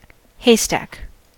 haystack: Wikimedia Commons US English Pronunciations
En-us-haystack.WAV